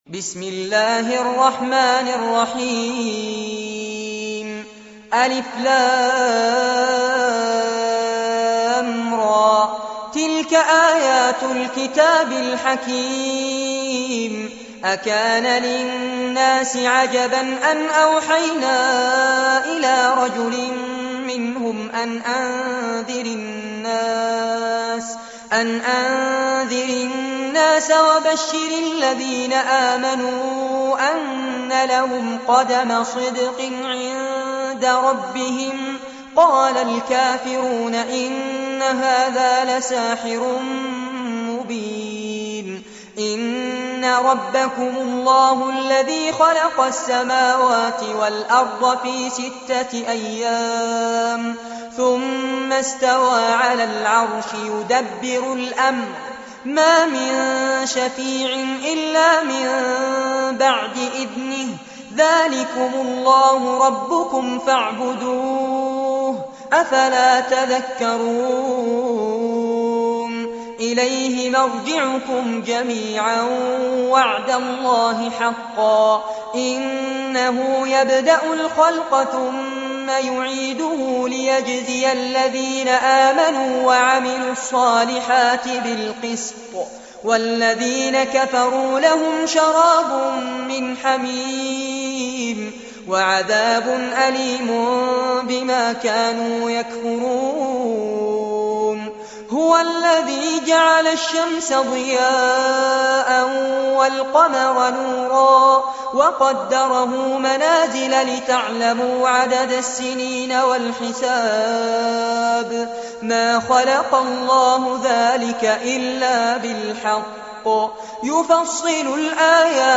سورة يونس- المصحف المرتل كاملاً لفضيلة الشيخ فارس عباد جودة عالية - قسم أغســـــل قلــــبك 2